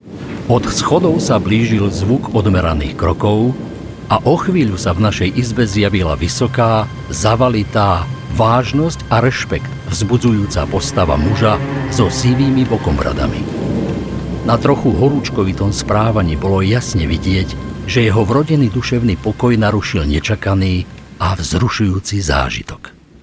Sprecher, Werbesprecher
Werbung TV L\'Oréal Paris False Lash Superstar